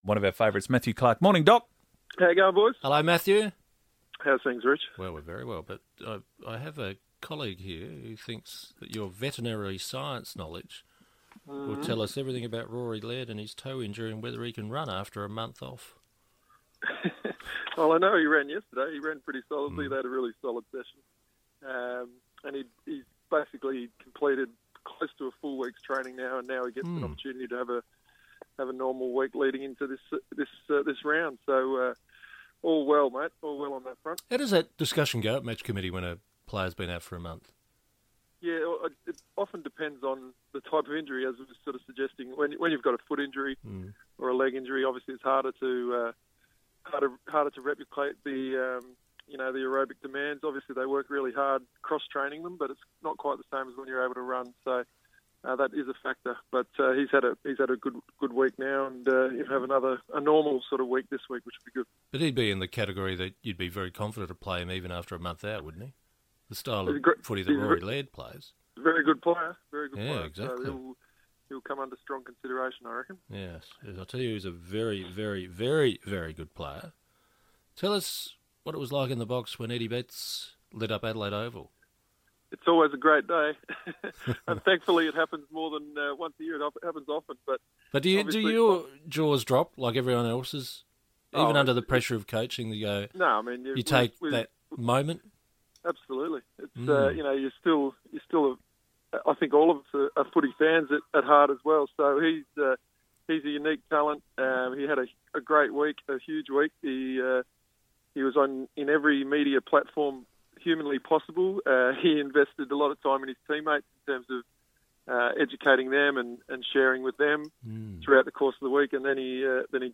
speaks to the FIVEaa breakfast team.